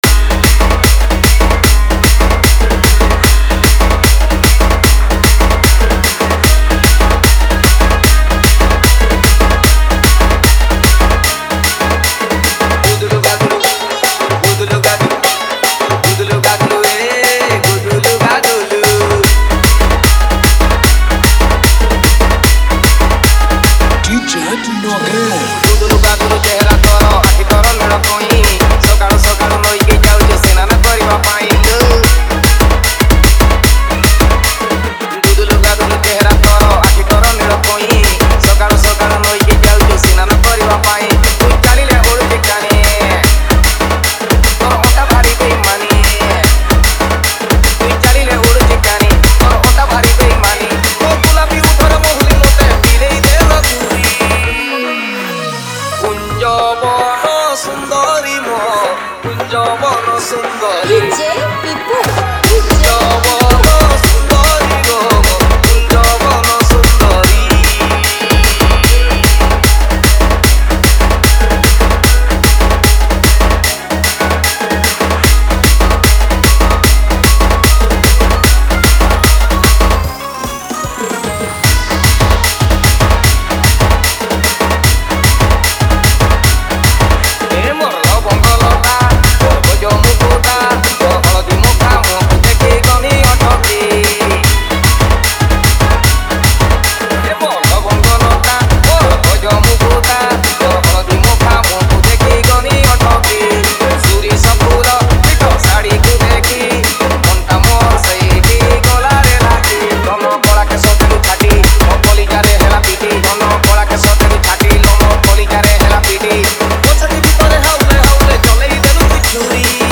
Single Dj Song Collection 2022 Songs Download